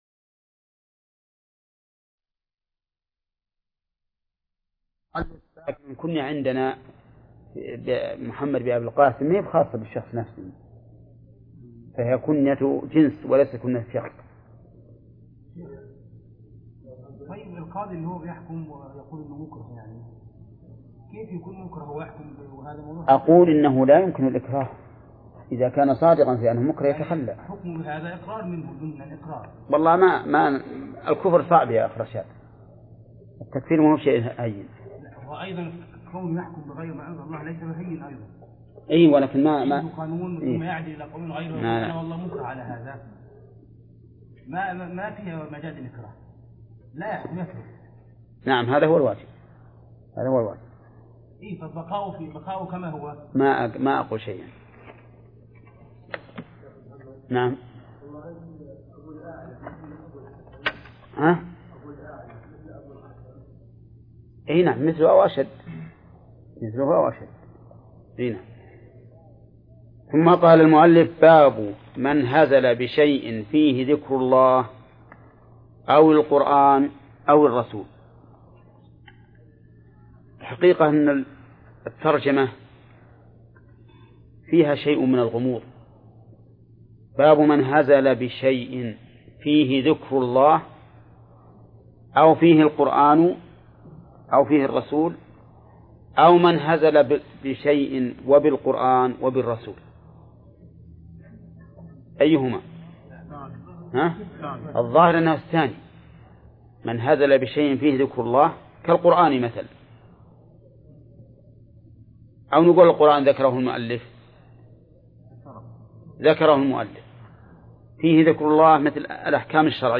درس (42) / المجلد الثاني : من صفحة: (267)، قوله: (باب من هزل بشيء فيه ذكر الله).، إلى صفحة: (284)، قوله: (وعن أبي هريرة: ..).